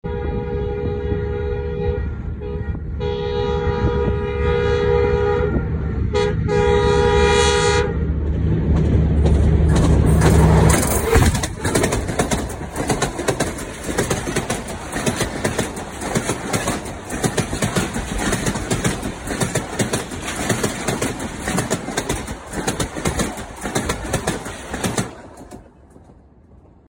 ZCU 20whistling+Tracksound